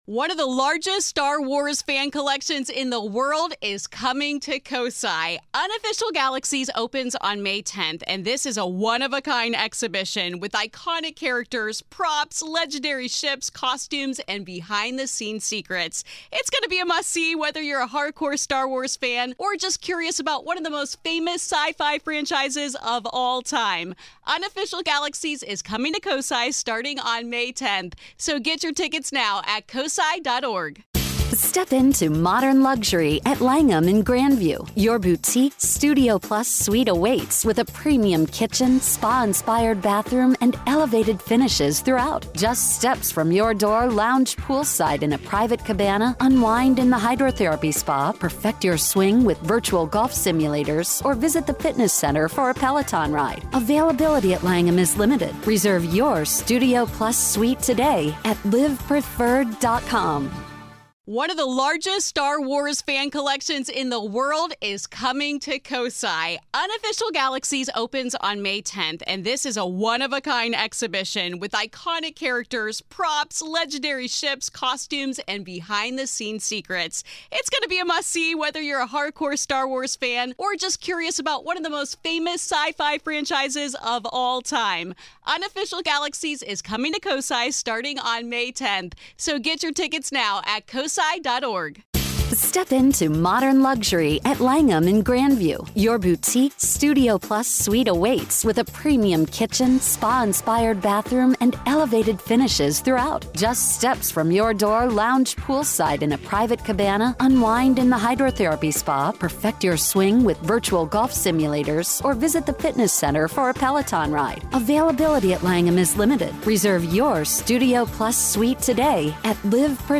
Listener call-in Sol Foundation/Hearing special
I am joined by YOU the listener (well, some of you) to discuss recent attendance of the Sol Foundation symposium & Congressional hearing